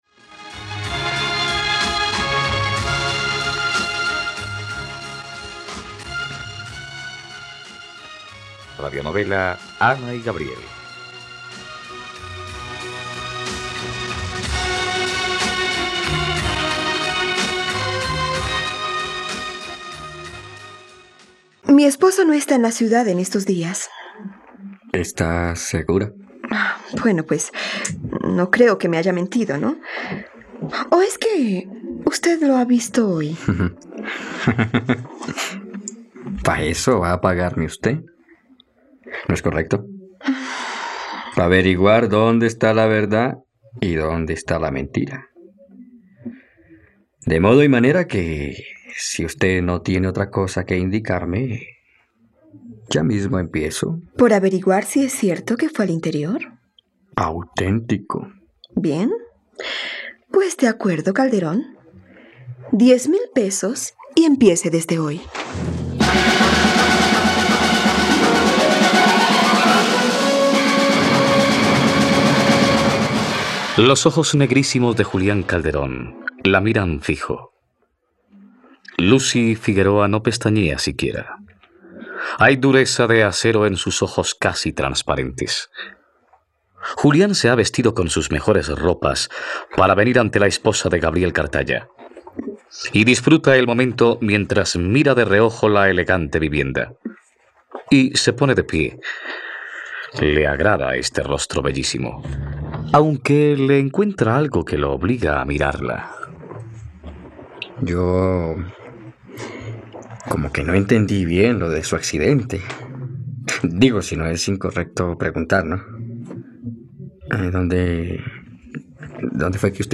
..Radionovela. Escucha ahora el capítulo 73 de la historia de amor de Ana y Gabriel en la plataforma de streaming de los colombianos: RTVCPlay.